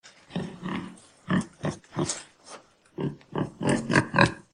На этой странице собраны натуральные звуки диких кабанов: от хрюканья и рычания до топота копыт по лесу.
Звук ворчания дикого кабана